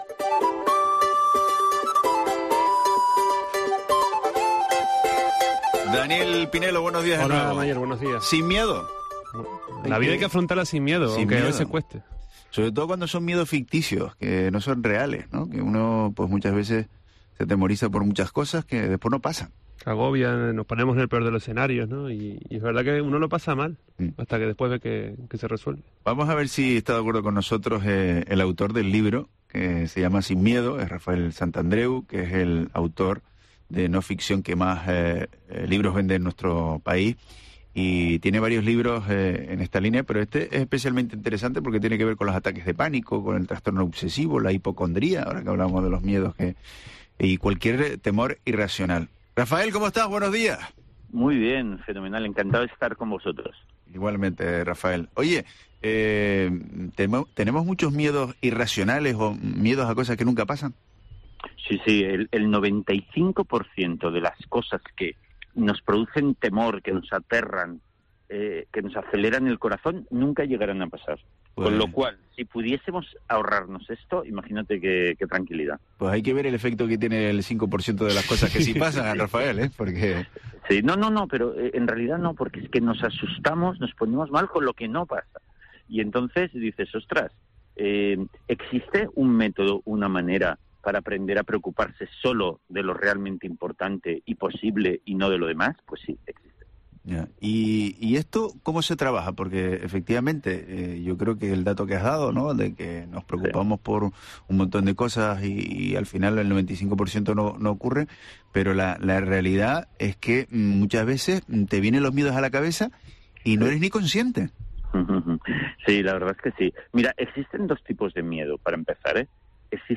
Rafael Santandreu, psicólogo y escritor